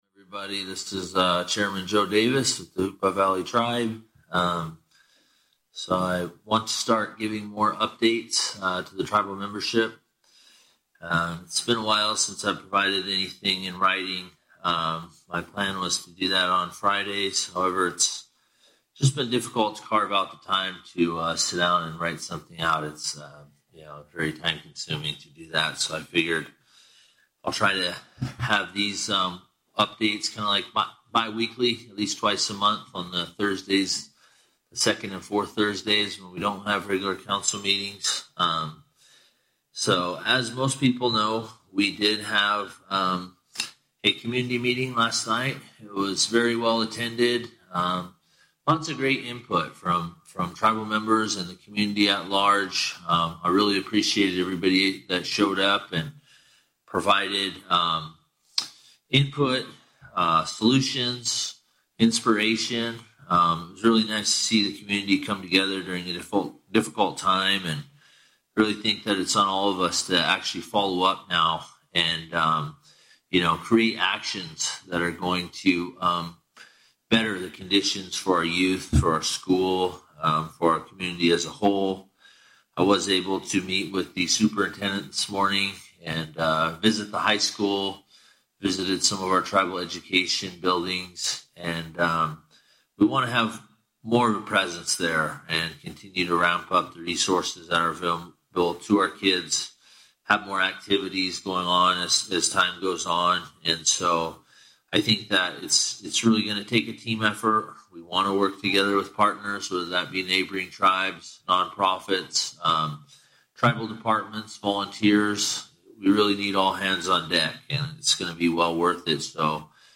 This is the first live Chairman’s Report via Facebook. This was recorded live and later posted here.